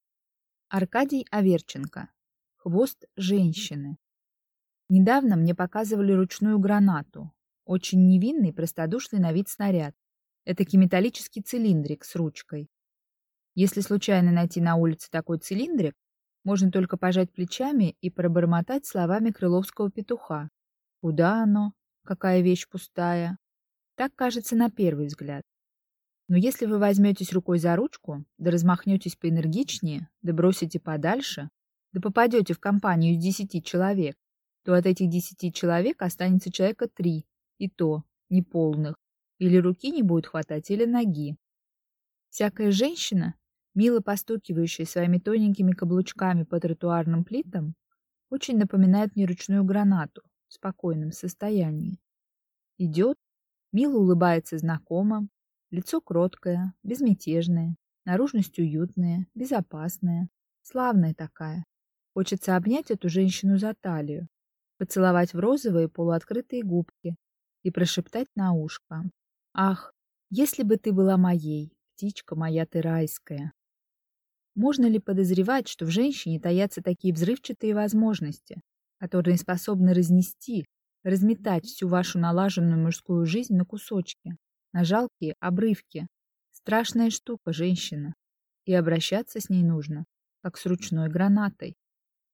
Аудиокнига Хвост женщины | Библиотека аудиокниг